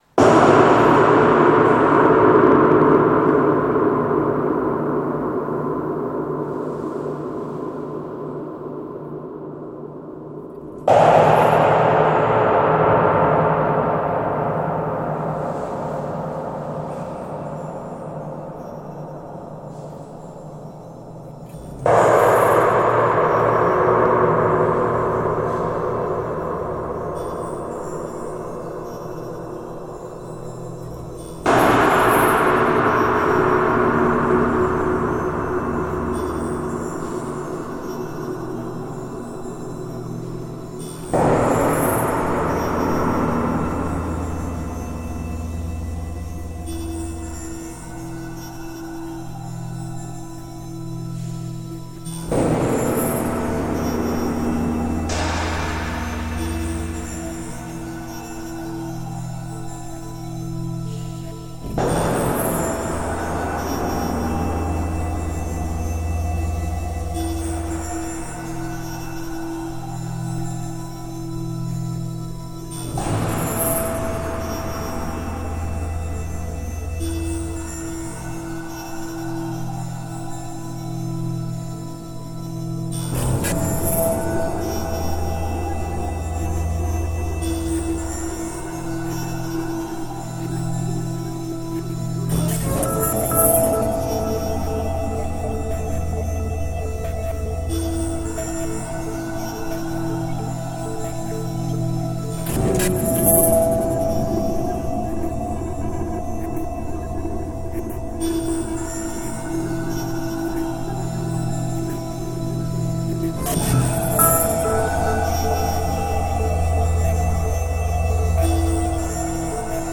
Reimagining the metallic interiors of prosecco wine tanks as the interior of satellites or spaceships orbiting the Earth, December 2014.